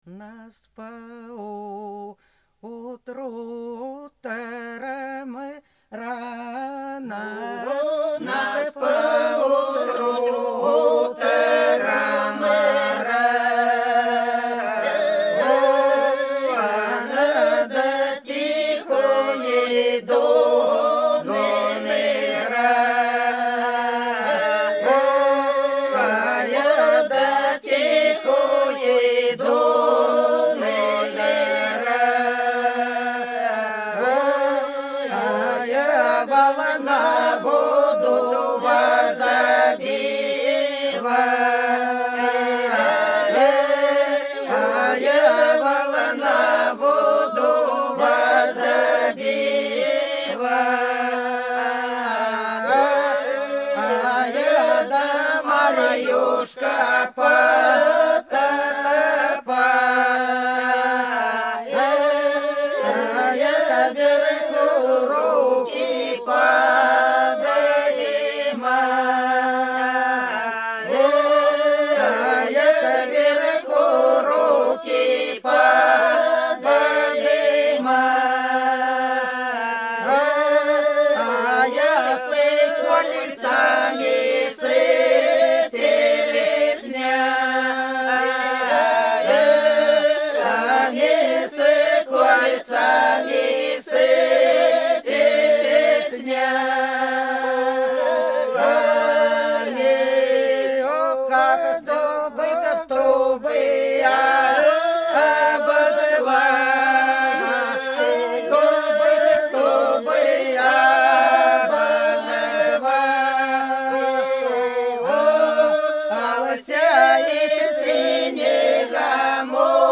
Пролетели все наши года У нас по утру рано – свадебная, на девишнике (Фольклорный ансамбль села Подсереднее Белгородской области)
09_У_нас_по_утру_рано_–_свадебная,_на_девишнике.mp3